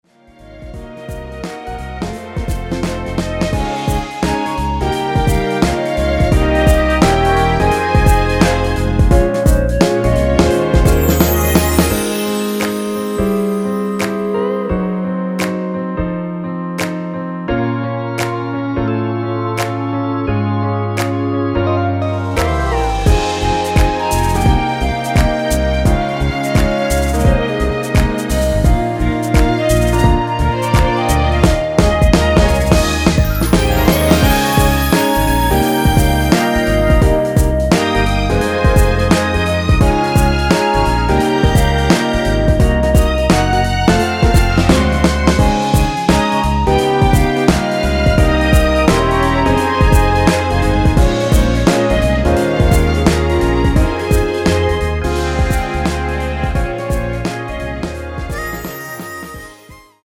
엔딩이 페이드 아웃이라서 노래하기 편하게 엔딩을 만들어 놓았으니 코러스 MR 미리듣기 확인하여주세요!
원키 (1절+후렴)으로 진행되는 멜로디 포함된 MR입니다.
Ab
앞부분30초, 뒷부분30초씩 편집해서 올려 드리고 있습니다.
중간에 음이 끈어지고 다시 나오는 이유는